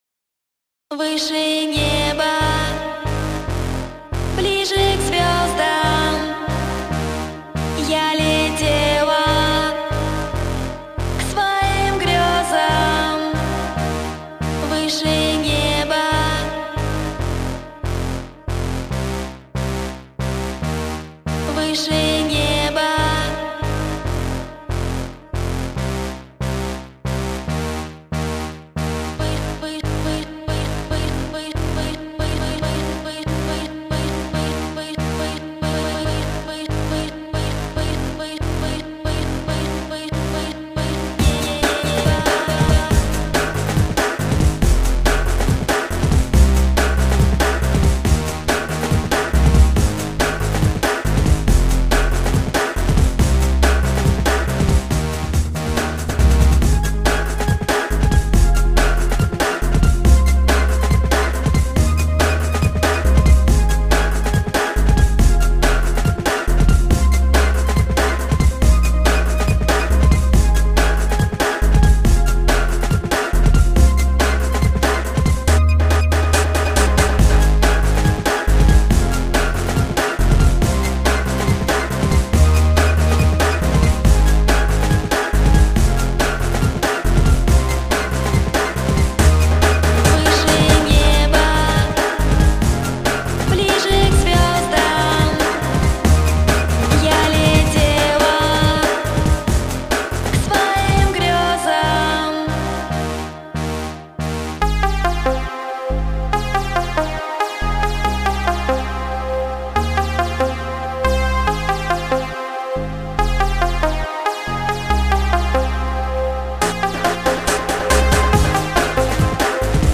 Немного лирический и в тоже время быстрый
микс с хорошим вокалом   d'nb 4.00 Mb